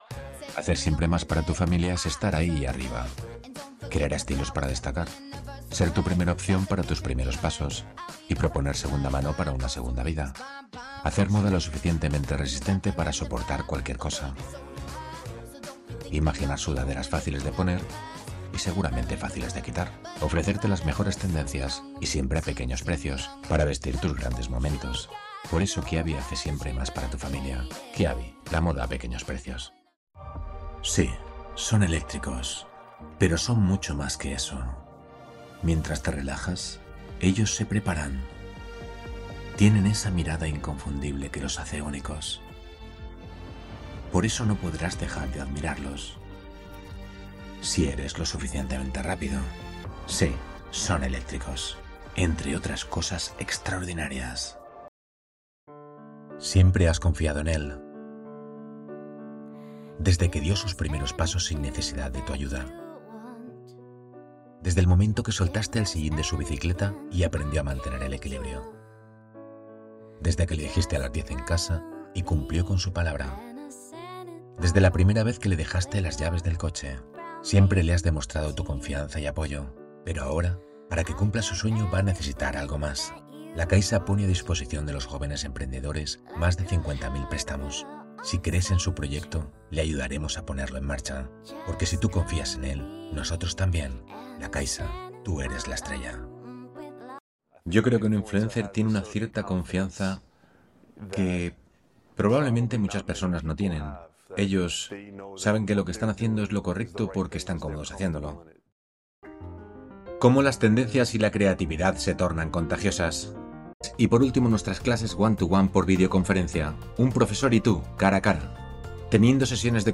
Accurate
Conversational
Friendly